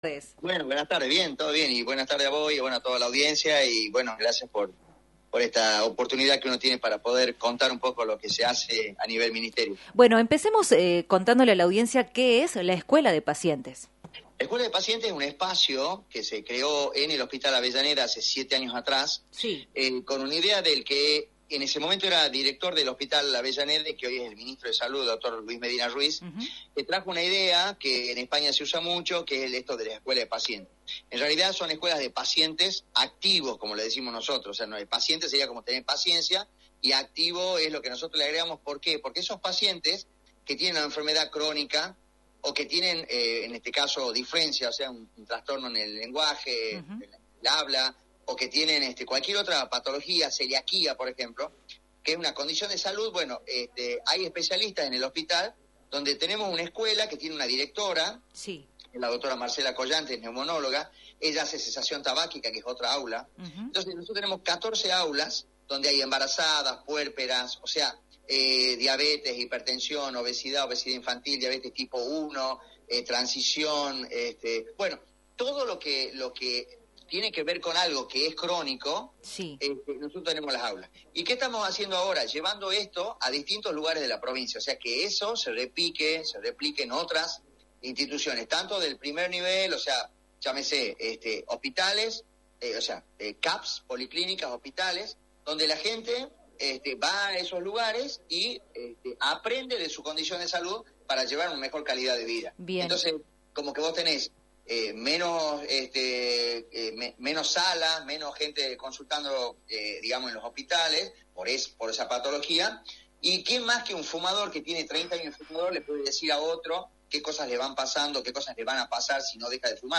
conversó vía telefónica en «Dos a la Tarde»
para el aire de la Rock&Pop FM 106.9.